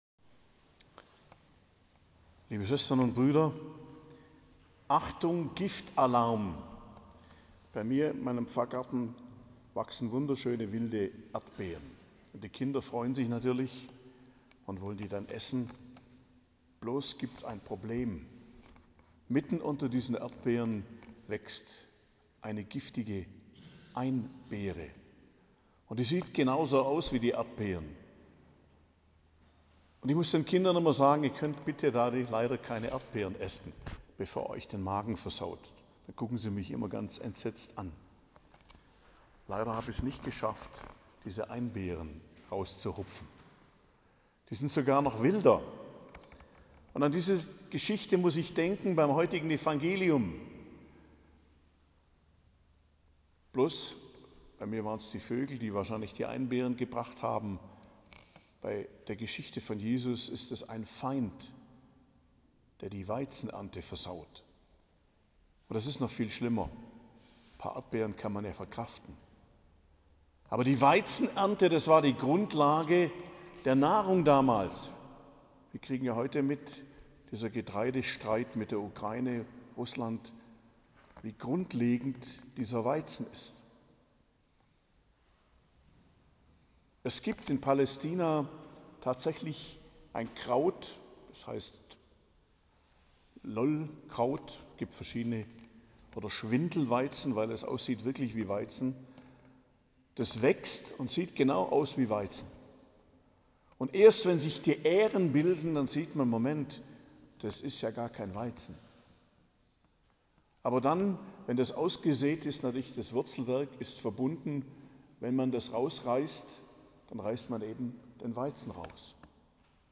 Predigt zum 16. Sonntag i.J., 23.07.2023 ~ Geistliches Zentrum Kloster Heiligkreuztal Podcast